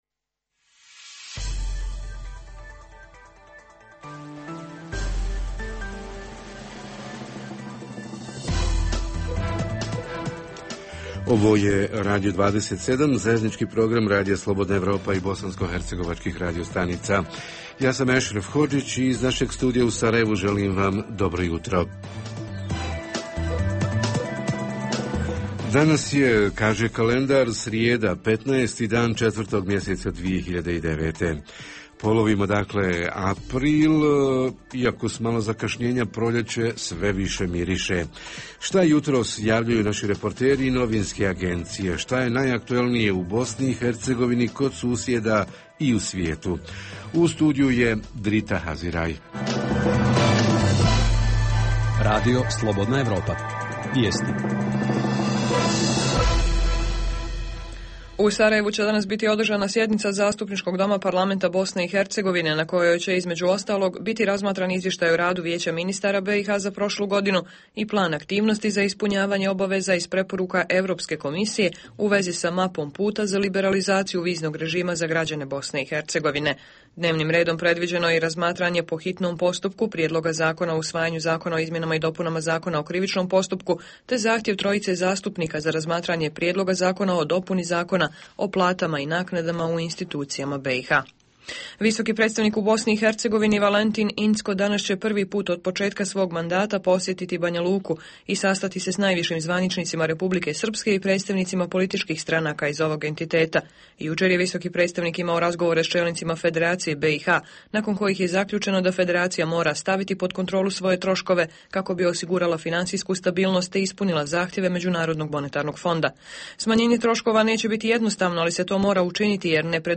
Kako, kome i koliko pomažu “esencijalne liste” lijekova, koje ne plaća direktno osiguranik već Zavod za zdravstveno osiguranje. Reporteri iz cijele BiH javljaju o najaktuelnijim događajima u njihovim sredinama.